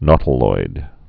(nôtl-oid)